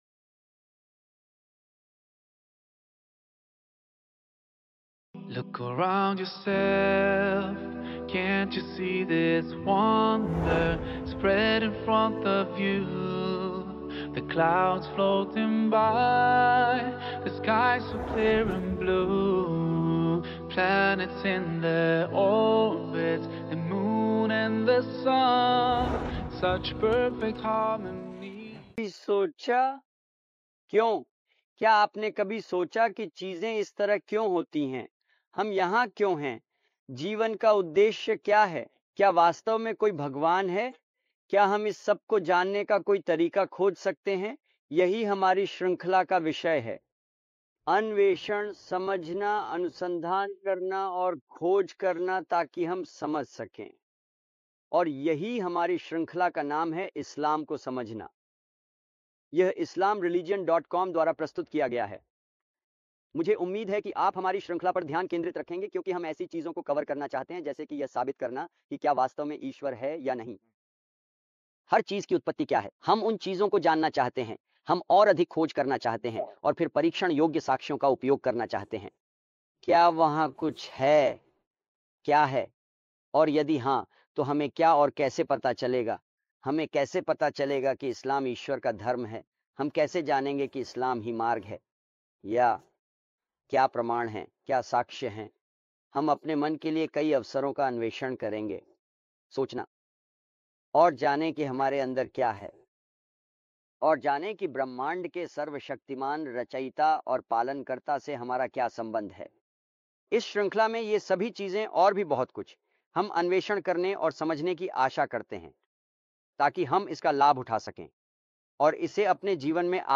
जॉर्डन के सुंदर दृश्यों और ऐतिहासिक स्थलों की पृष्ठभूमि में फिल्माई गई है। इस एपिसोड में वह श्रृंखला का परिचय देते हैं।